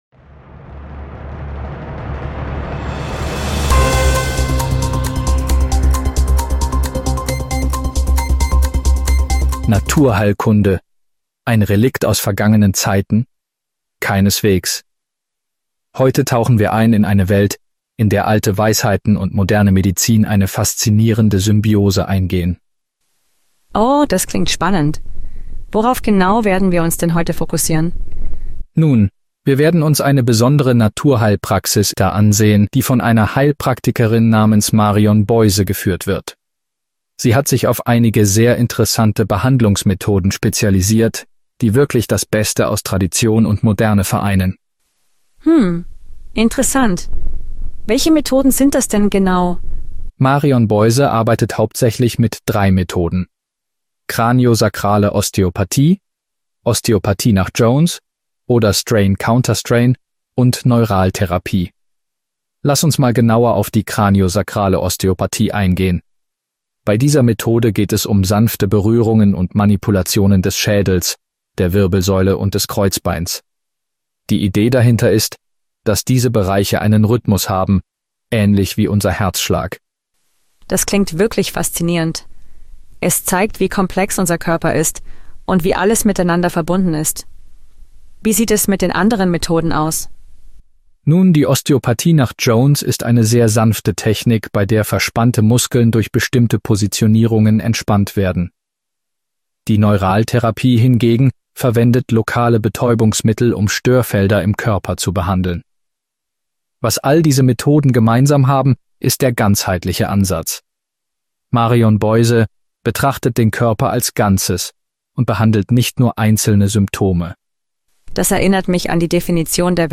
Ein Gespräch über die Praxis: